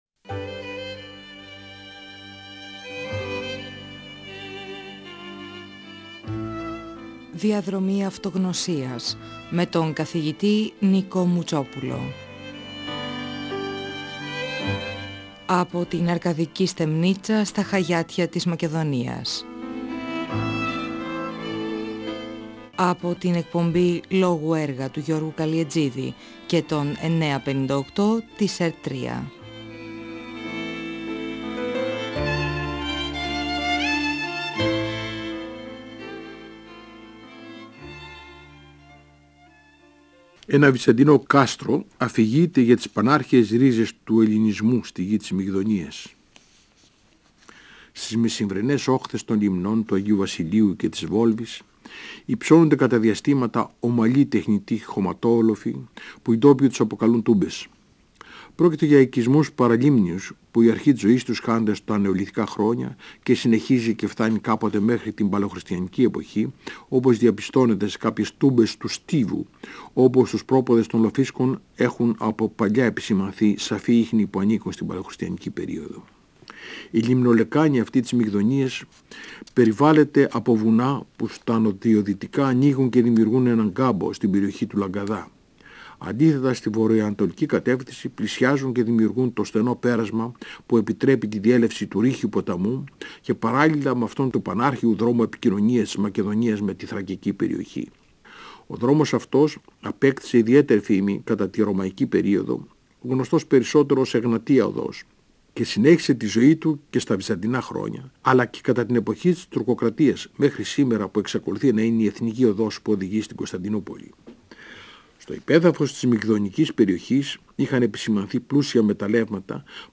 Ο αρχιτέκτονας Νικόλαος Μουτσόπουλος (1927-2019) μιλά για τους παραλίμνιους οικισμούς τών νεολιθικών χρόνων στις λίμνες του Αγίου Βασιλείου και της Βόλβης, για τα μεταλλεύματα της περιοχής, την αρχαία μακεδονική πόλη Αρέθουσα, την αρχαία πηγή της, την ακρόπολή της, τον ναό τής Αθηνάς.